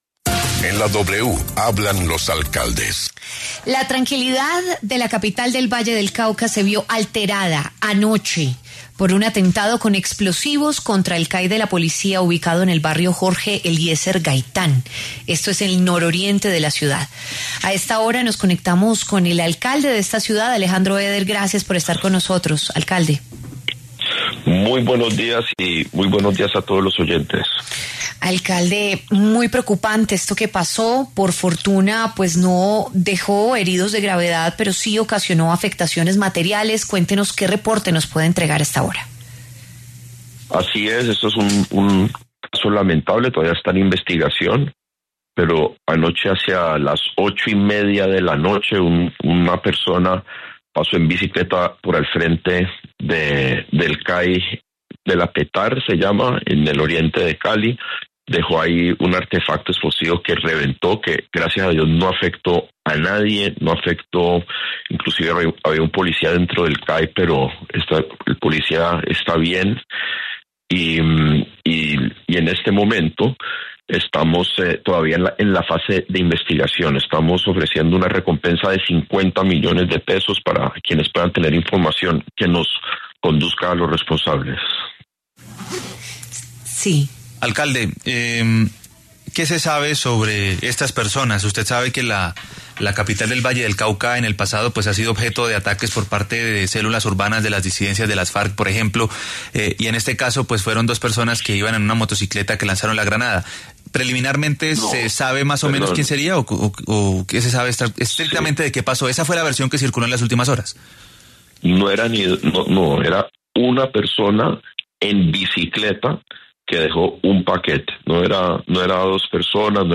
El alcalde de Cali, Alejandro Eder, habló ante los micrófonos de W Fin de Semana sobre el atentado con explosivos contra un CAI de la capital del Valle del Cauca.